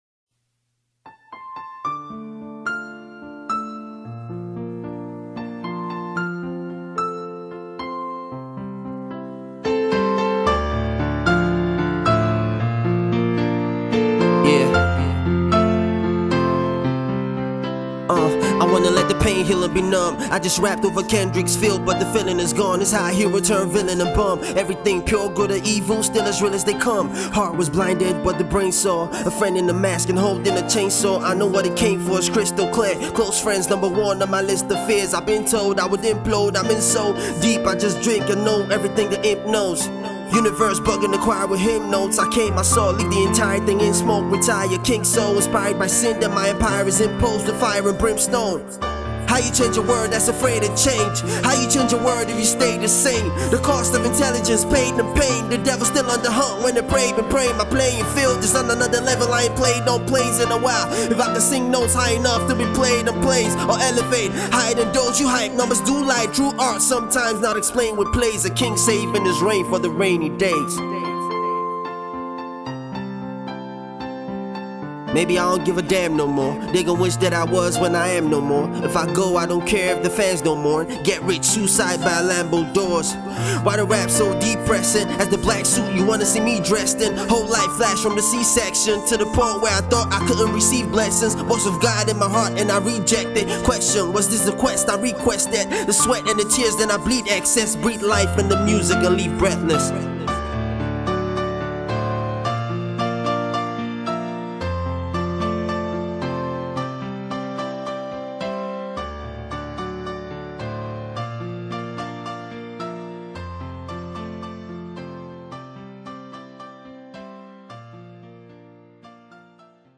raps over a piano rendition